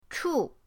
chu4.mp3